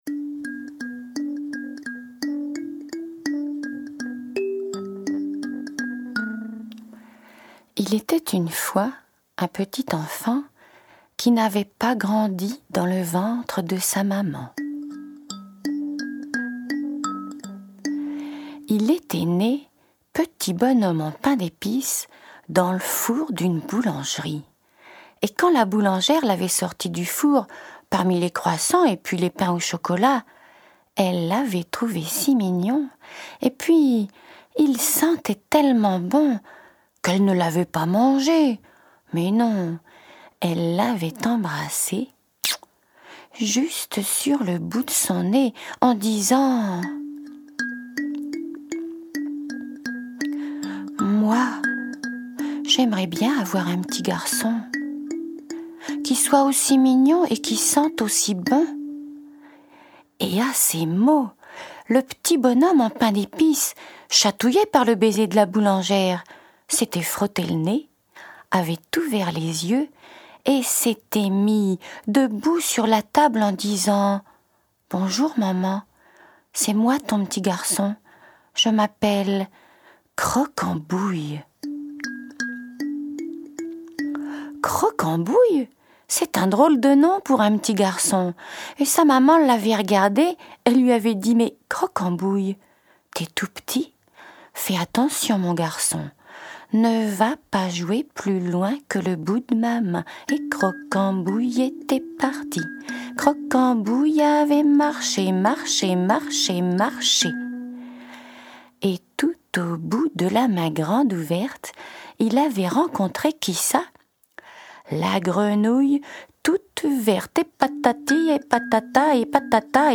2020-11-06 Contes à croquer pour les petites bouilles !